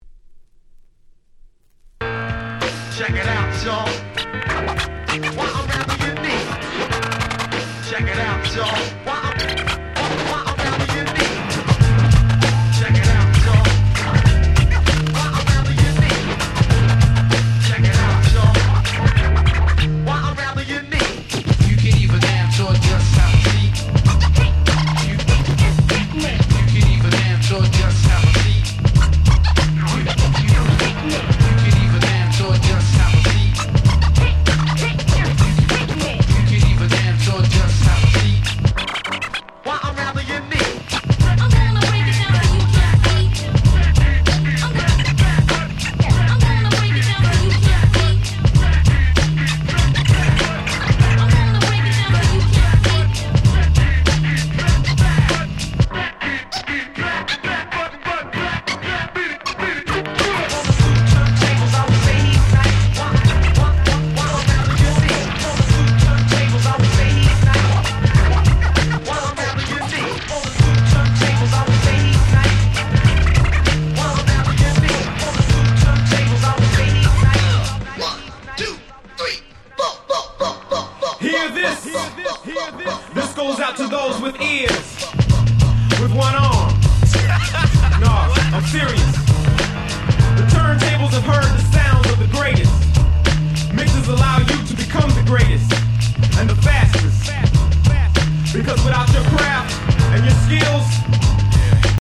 94’ Super Nice 90’s Hip Hop !!